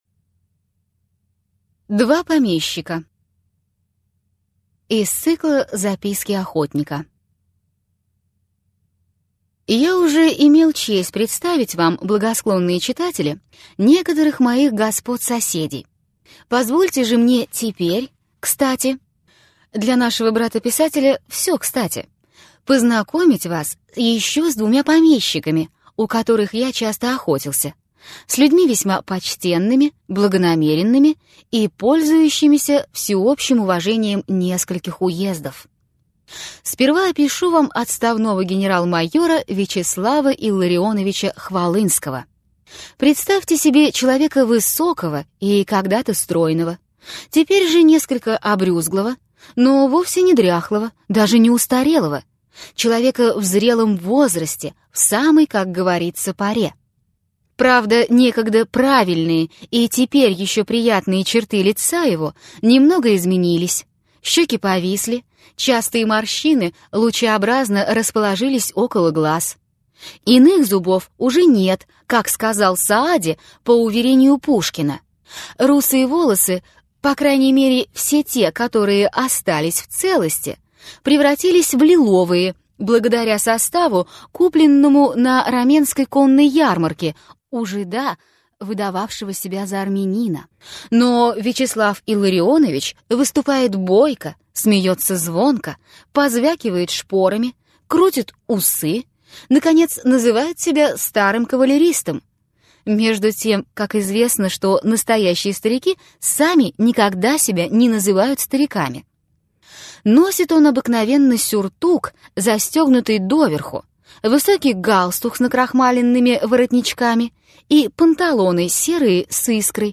Аудиокнига Из записок охотника: Чертопханов и Недопюскин. Два помещика. Ермолай и мельничиха | Библиотека аудиокниг